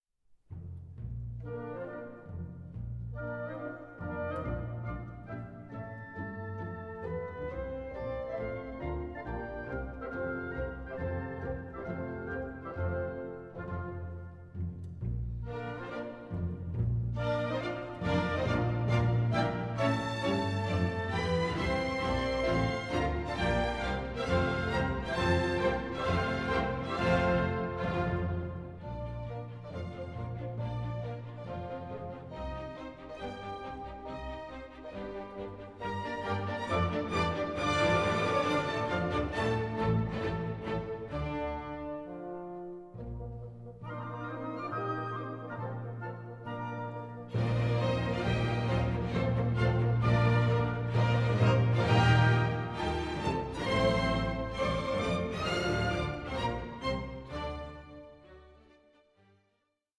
Dialogue